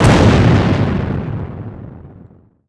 laser_explo_01.wav